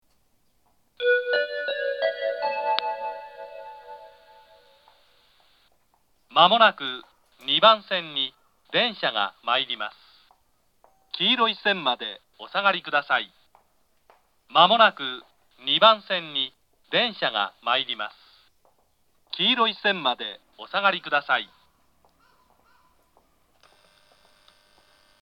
東海道型(男性)
接近放送（簡易）